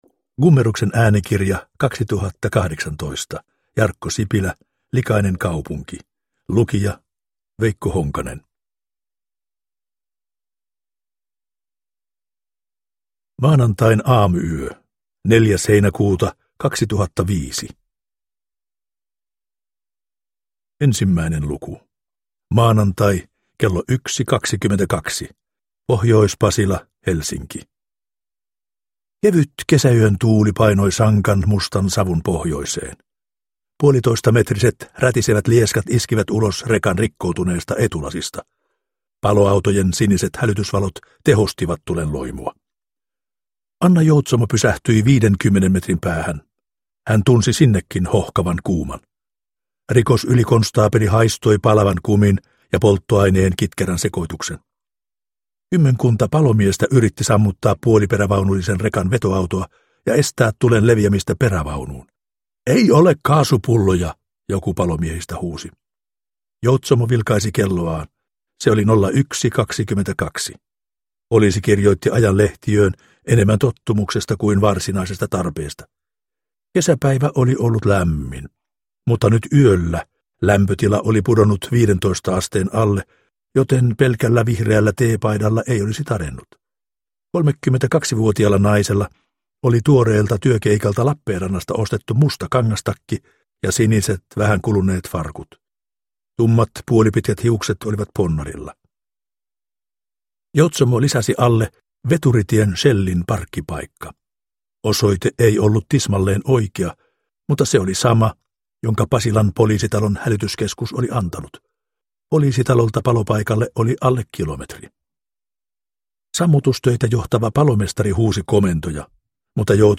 Likainen kaupunki – Ljudbok – Laddas ner